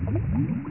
lava1.mp3